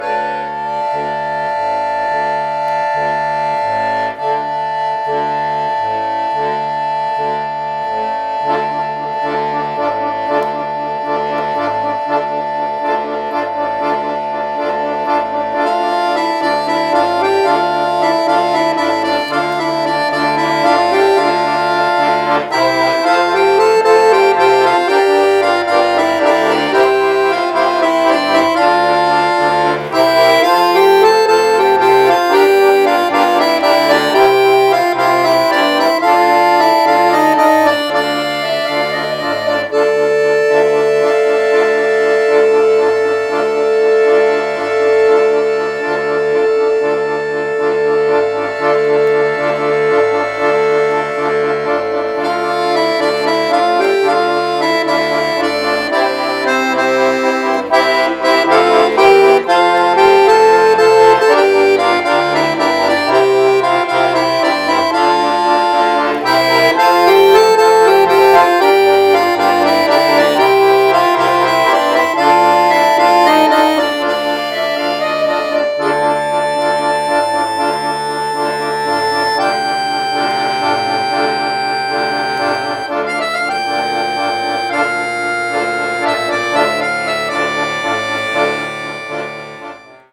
Es ist nur ein Probenmitschnitt, also bitte ich darum, Tippfehler, Unsauberheiten und sonstige "Personalisierungen" zu entschuldigen.